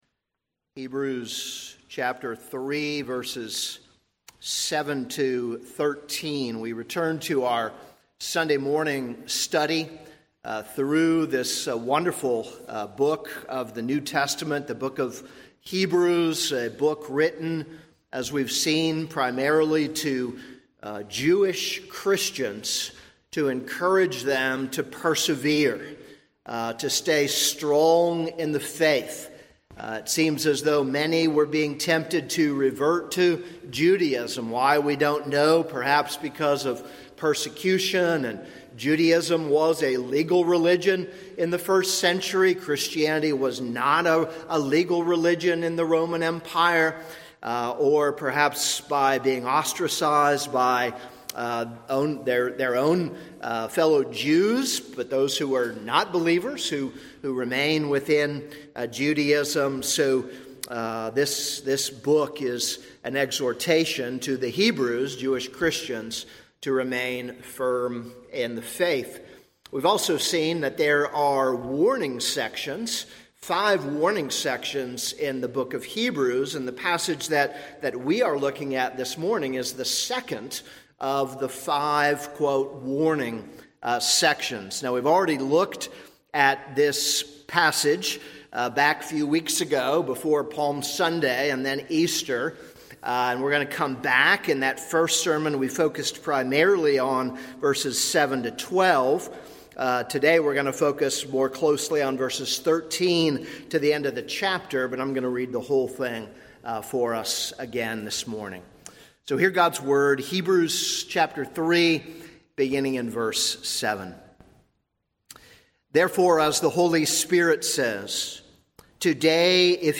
This is a sermon on Hebrews 3:7-19.